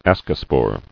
[as·co·spore]